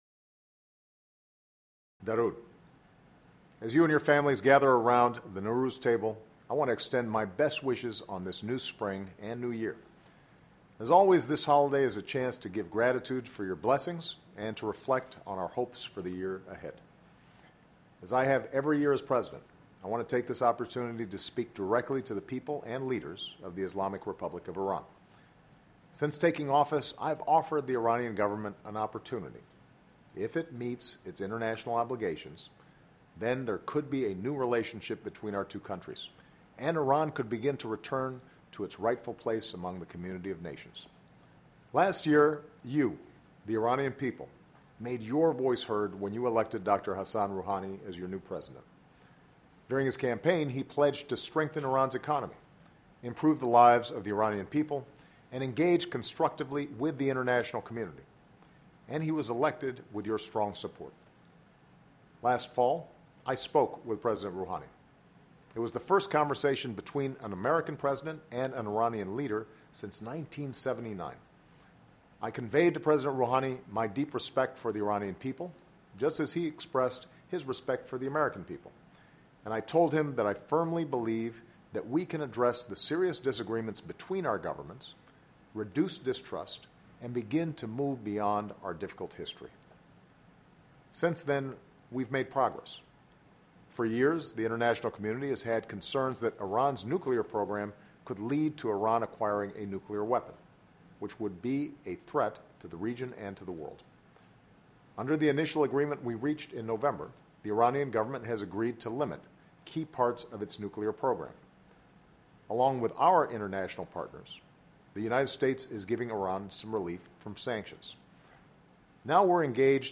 President Barack Obama, in a special message, sends best wishes to everyone celebrating Nowruz, the Persian or Iranian New Year. The President speaks directly to the people and leaders of the Islamic Republic of Iran about the possibility of a new chapter in the history of Iran and its role in the world--including a better relationship with the United States.